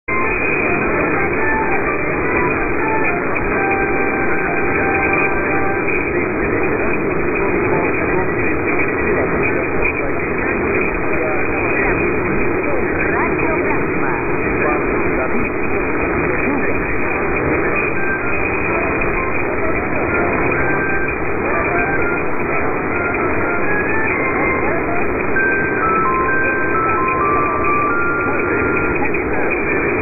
1000 | CUBA | R. Granma, Media Luna, MAR 26 @ 0101 - Woman with "Radio Granma" ID, chimes; poor but dominant.